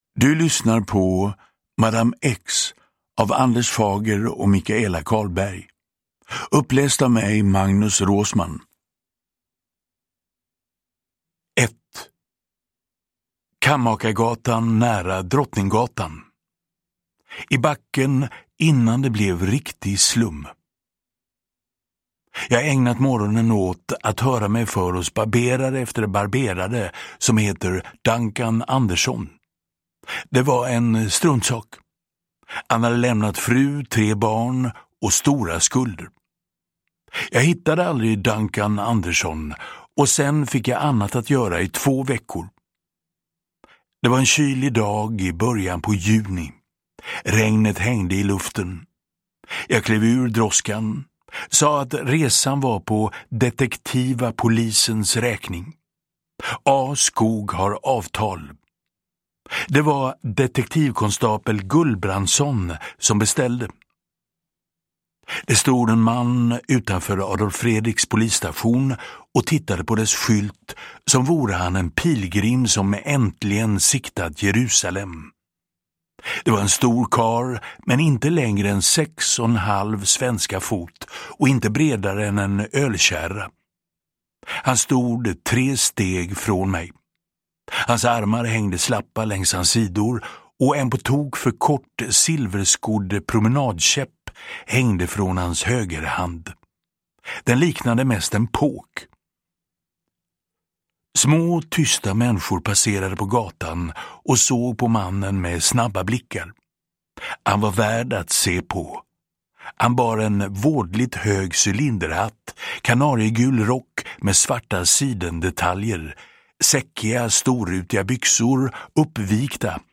Madame X : en kriminalroman om 1894 (ljudbok) av Fager & Carlberg